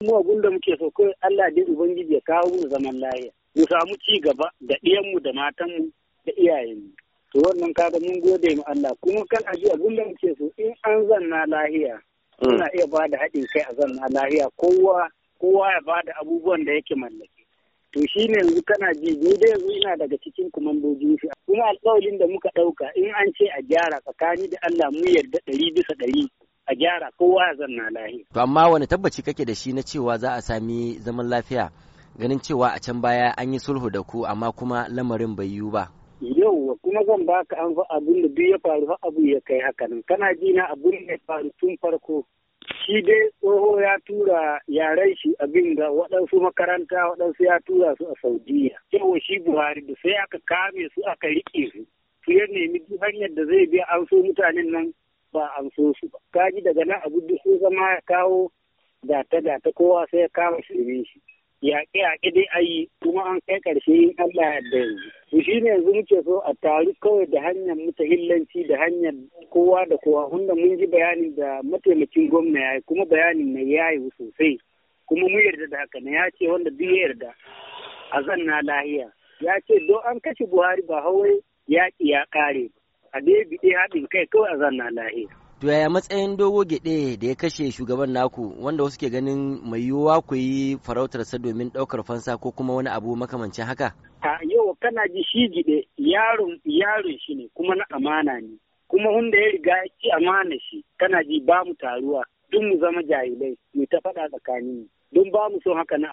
A cikin wata hira da Muryar Amurka